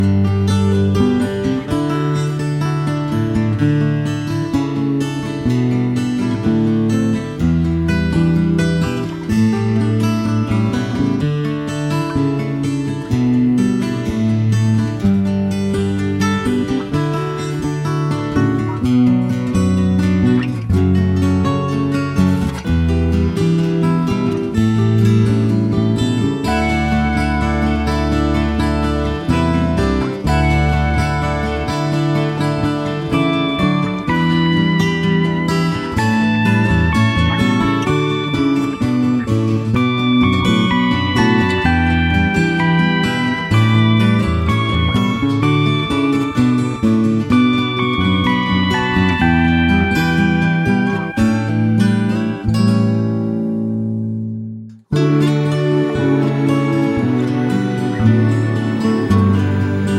no Backing Vocals Country (Male) 2:40 Buy £1.50